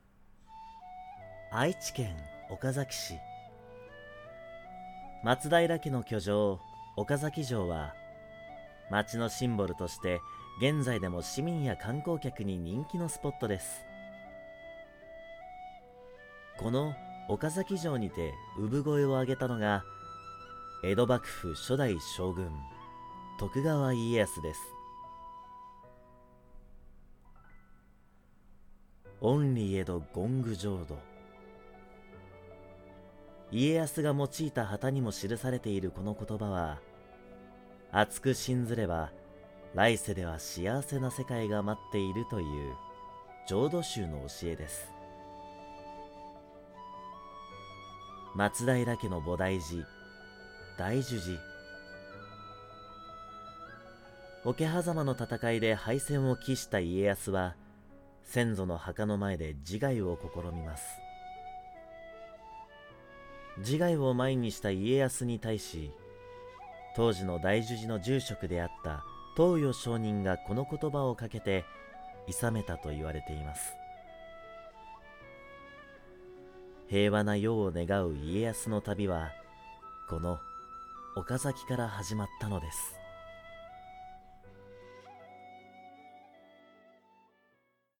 得意な仕事 中～低音域の少年、青年、オカマ、バラエティ系ナレーション、英語発音系
– ナレーション –
ナレーション　岡崎市の紹介.mp3